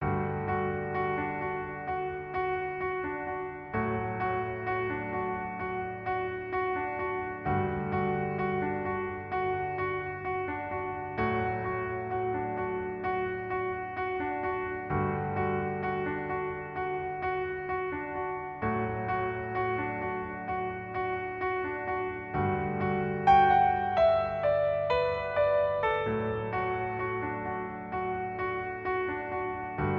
• 🎹 Instrument: Piano Solo
• 🎼 Key: D Major
• 🎶 Genre: Rock
expressive piano solo arrangement